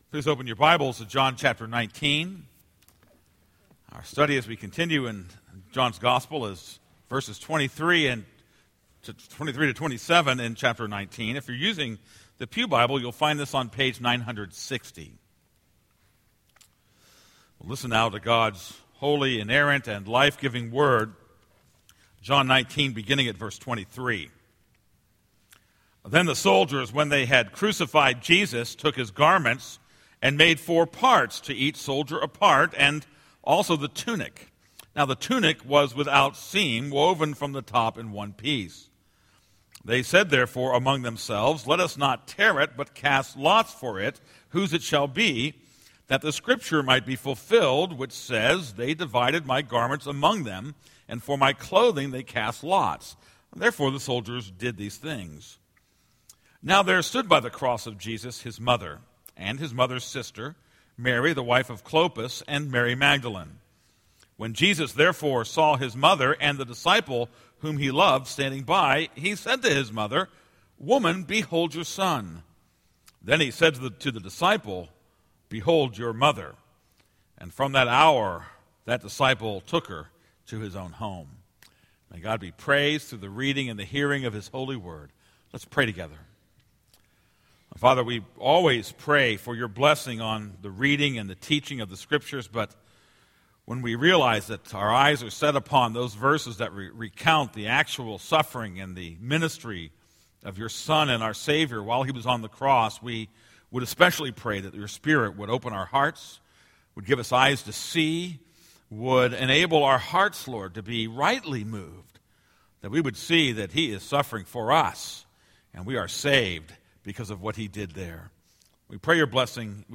This is a sermon on John 19:23-27.